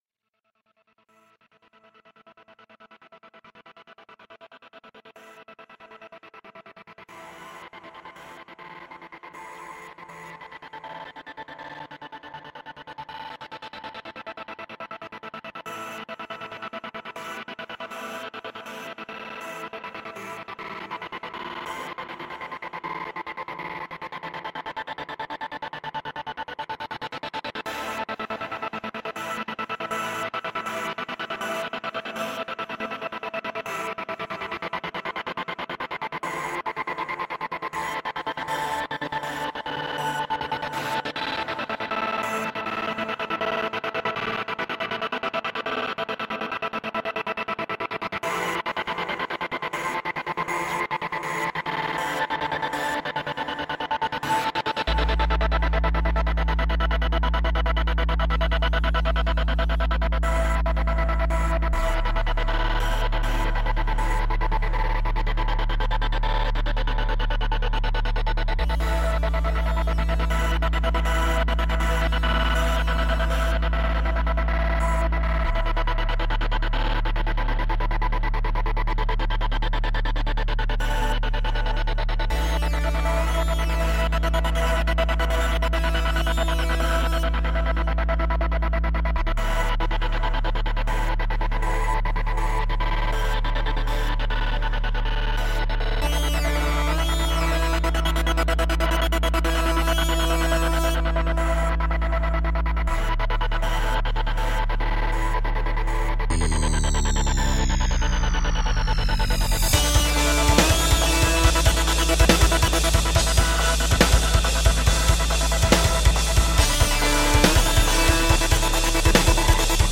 Electronica, Experimental, Hard Electronic